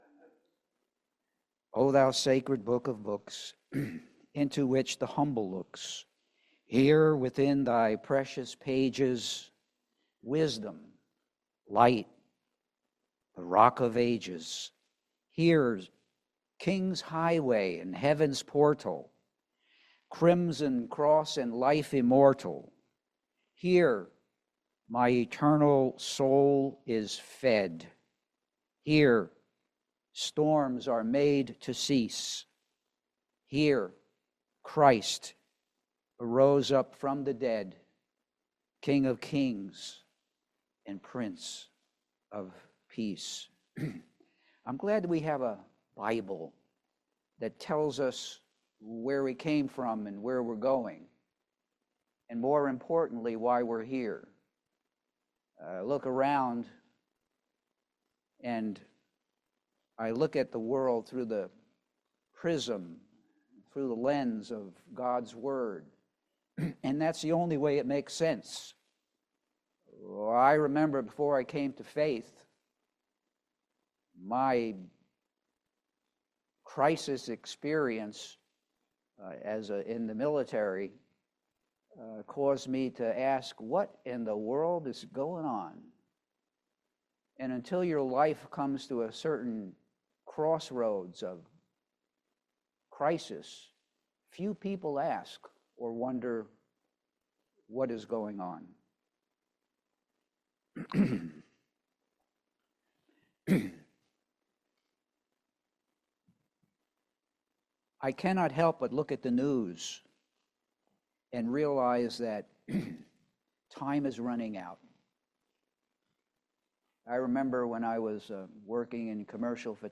Hebrews 11:1,7 Service Type: Family Bible Hour Life is a series of choices.